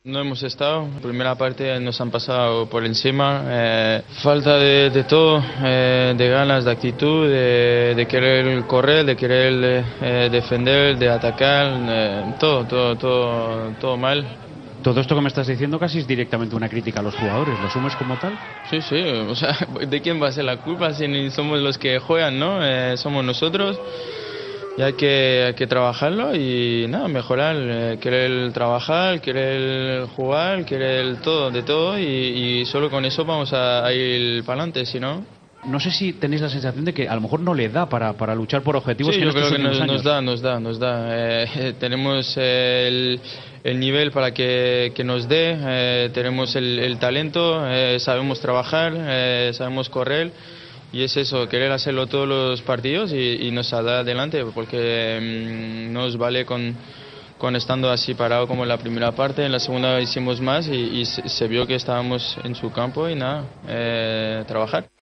Antoine Griezmann, en los micrófonos de Movistar, crítico tras el 0-3: "Nos ha faltado de todo: ganas, actitud, correr... Todo, todo. Hay que querer trabajar y mejorar".